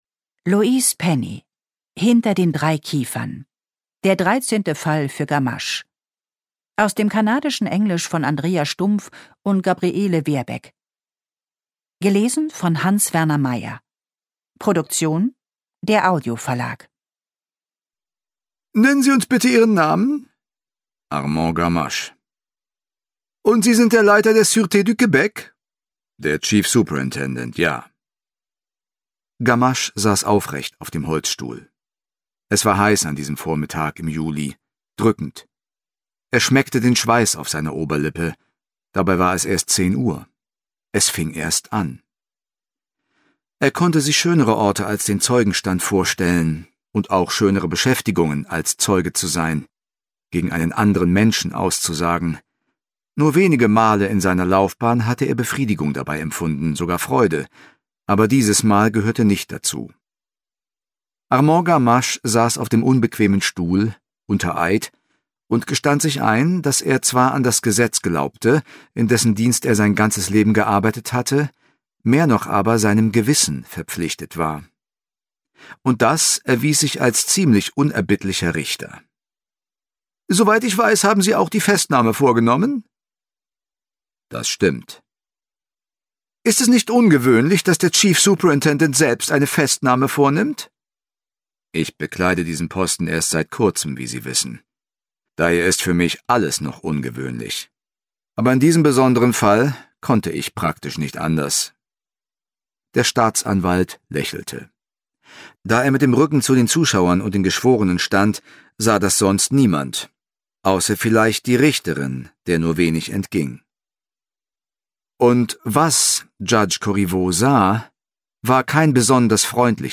Ungekürzte Lesung mit Hans-Werner Meyer (2 mp3-CDs)
Hans-Werner Meyer (Sprecher)